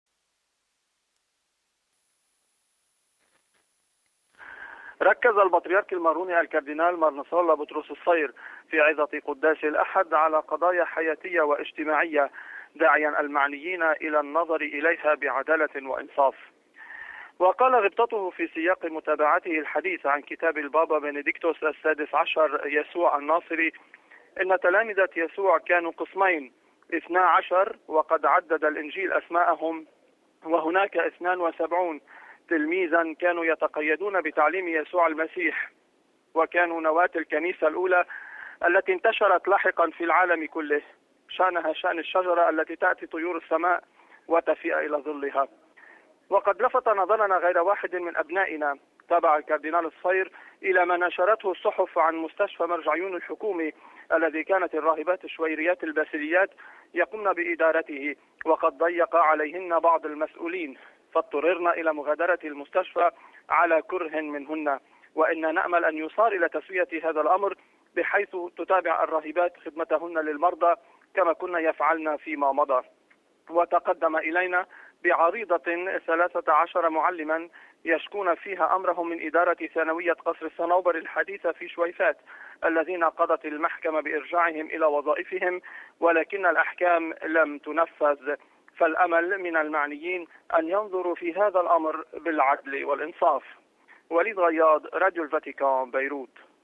عظة البطريرك الماروني مار نصرالله بطرس صفير في بكركي لبنان